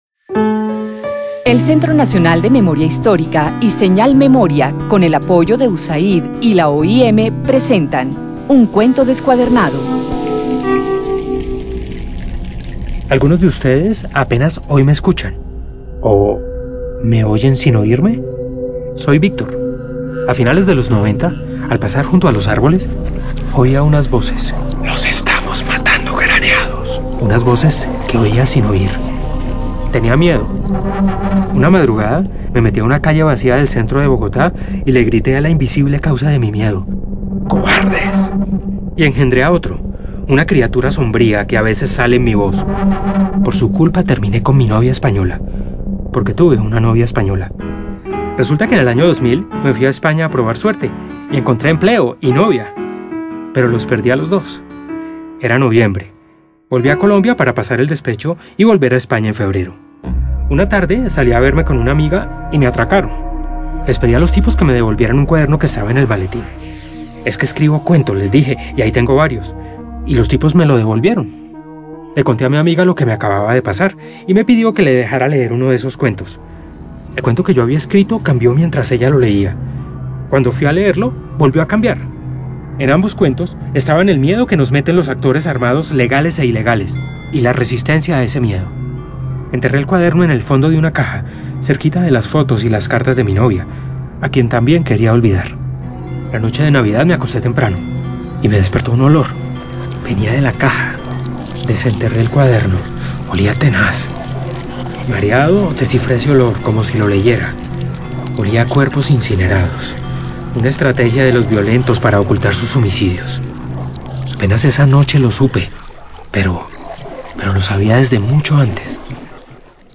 Cuentos, cuñas, programas. Serie Radial ¡Basta Ya! La Vida Cuenta.